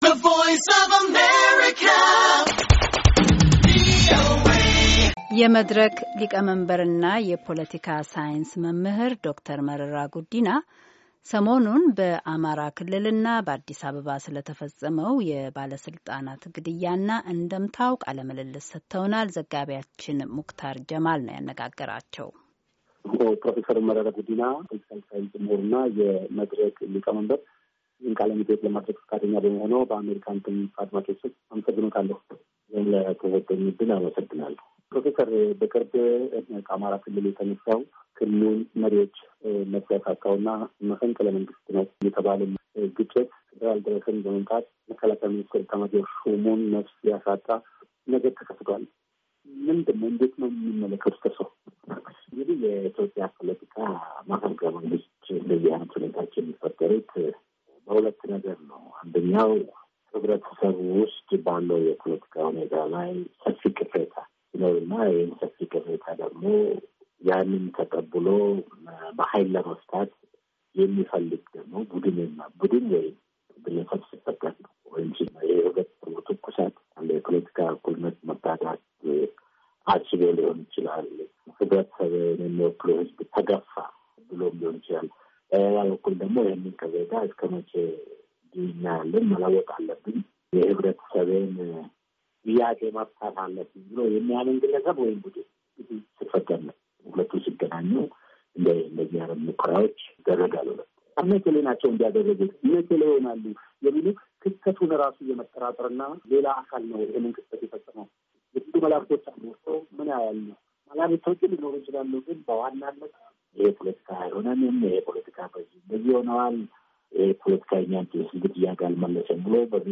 የመድረክ ሊቀመንበርና የፖለቲካ ሳይንስ መምህር ዶ/ር መረራ ጉዲና ሰሞኑን በአማራ ክልልና በአዲስ አበባ ሰለተፈፀመው የባለሥልጣናት ግድያና እንድምታው ቃለ ምልልስ ሰጥተውናል፡፡